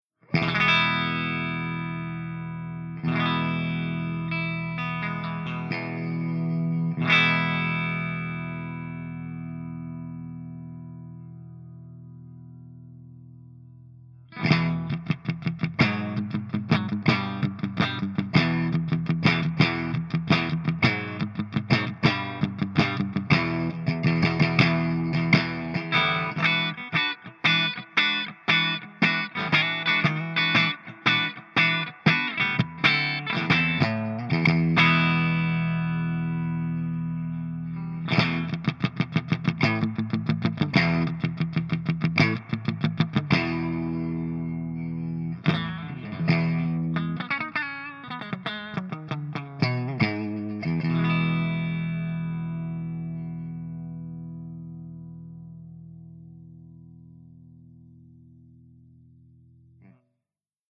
097_HIWATT_STANDARDCRUNCH_GB_HB.mp3